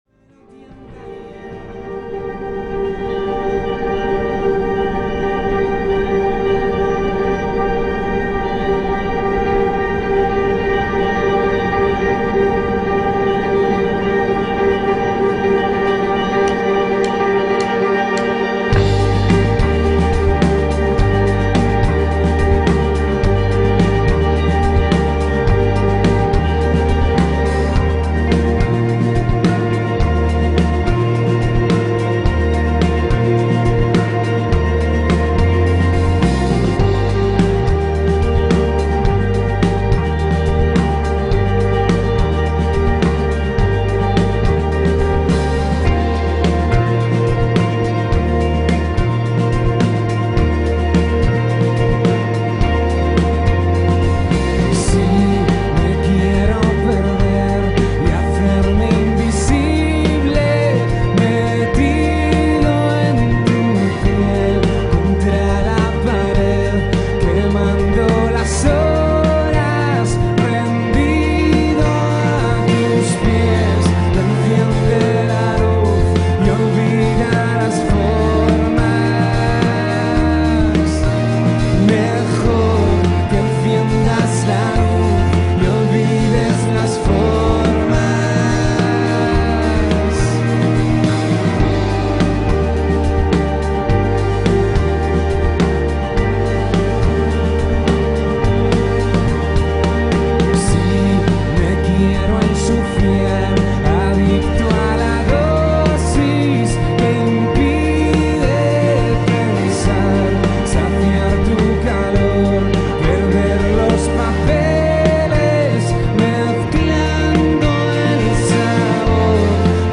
Spanish Indie/Alternative band